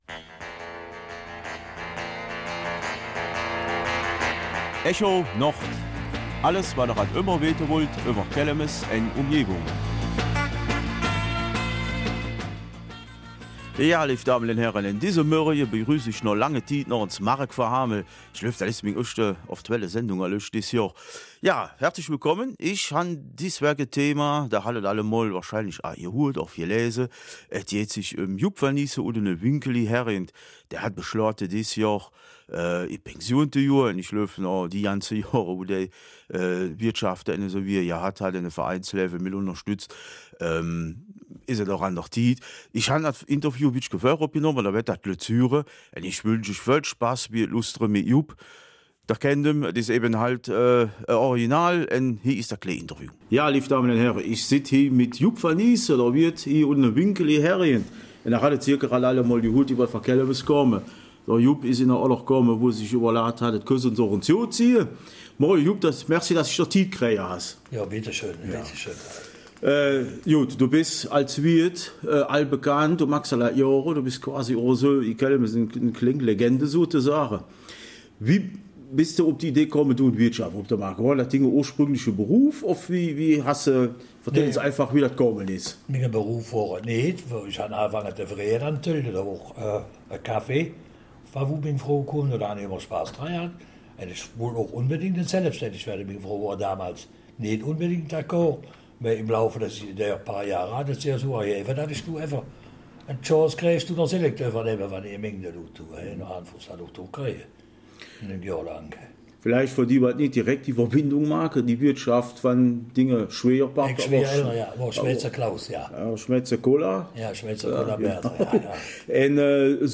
Kelmiser Mundart